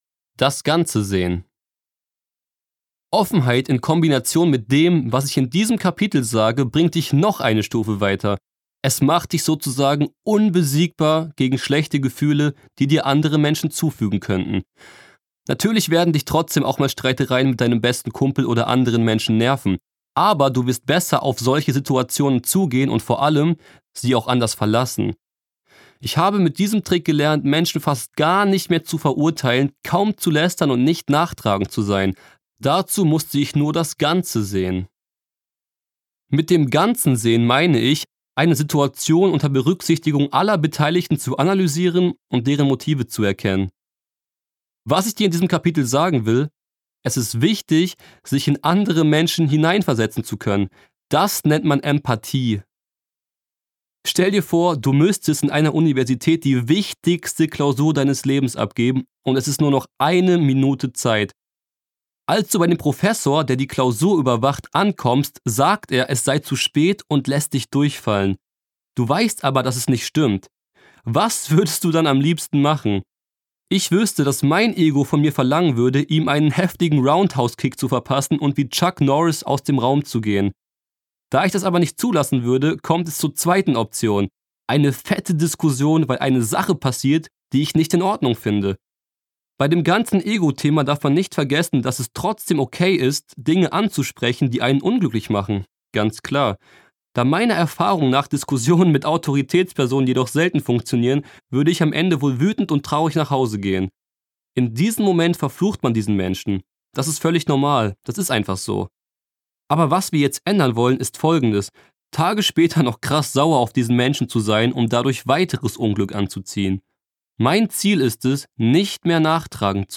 Marcel Althaus (Sprecher)
2017 | 2. Auflage, Ungekürzte Ausgabe